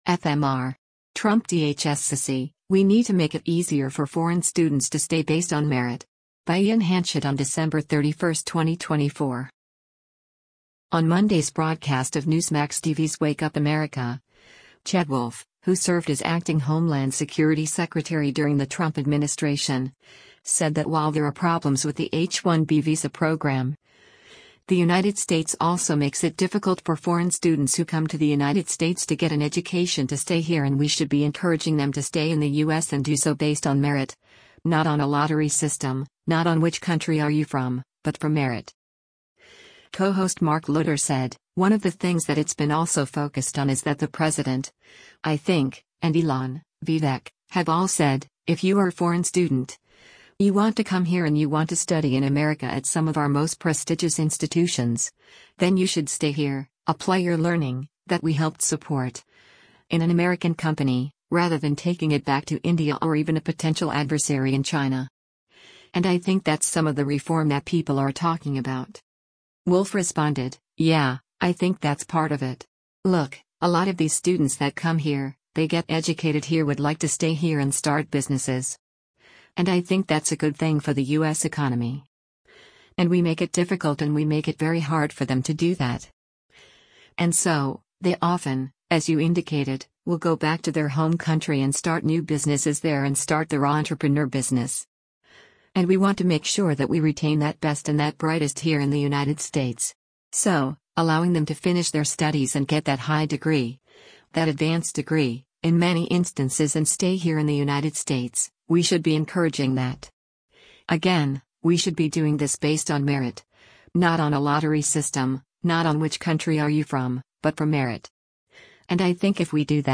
On Monday’s broadcast of Newsmax TV’s “Wake Up America,” Chad Wolf, who served as acting Homeland Security Secretary during the Trump administration, said that while there are problems with the H-1B visa program, the United States also makes it difficult for foreign students who come to the United States to get an education to stay here and “we should be encouraging” them to stay in the U.S. and do so “based on merit, not on a lottery system, not on which country are you from, but from merit.”